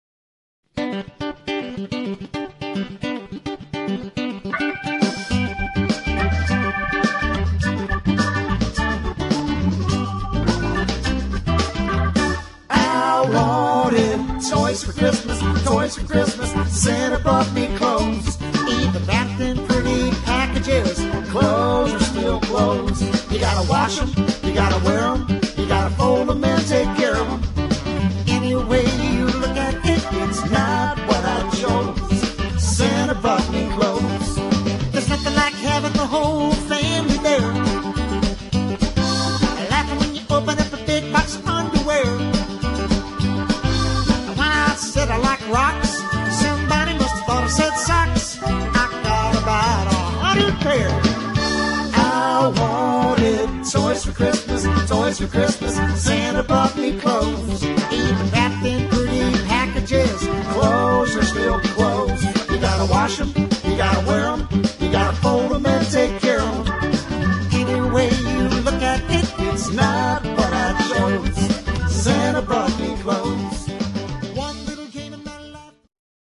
--funny Christmas music